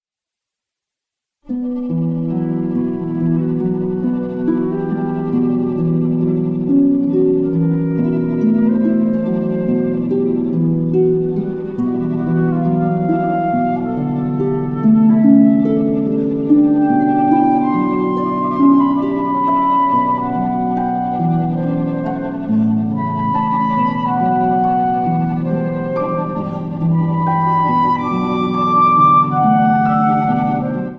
Harp
Flute
Viola Released